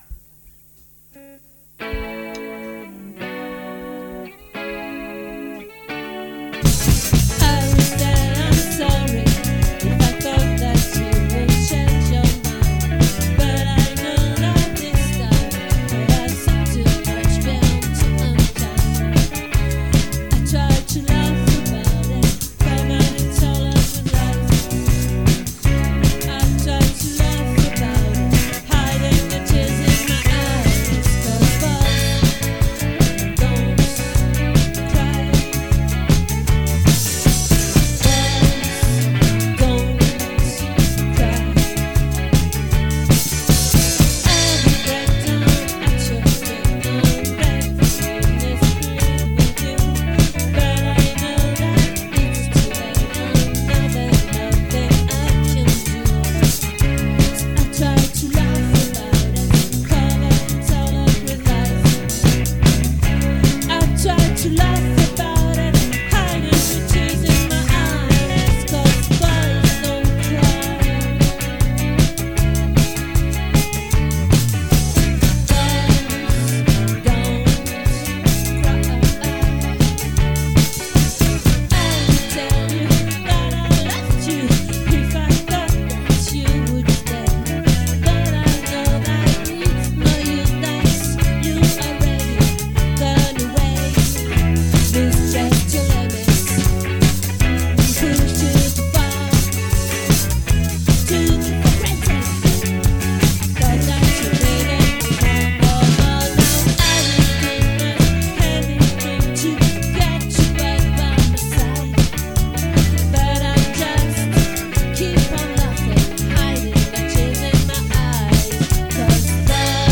🏠 Accueil Repetitions Records_2023_01_04_OLVRE